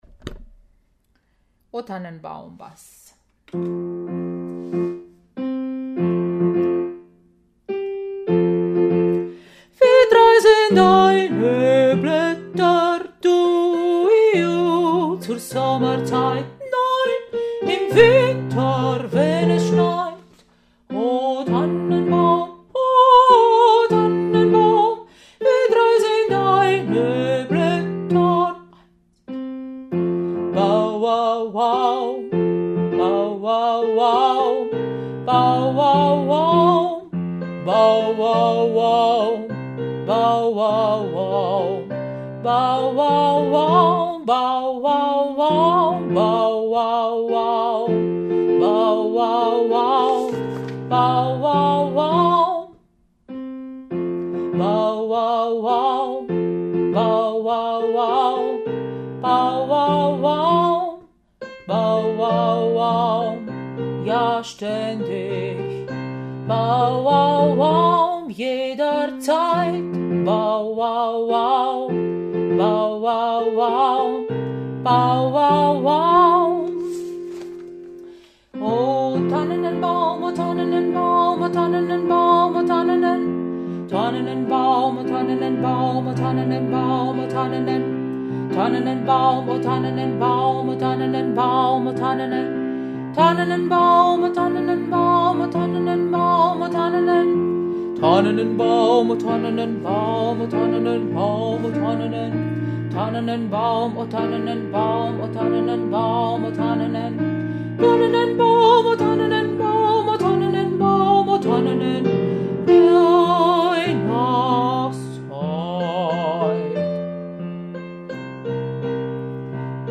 Oh-Tannenbaum-Bass.mp3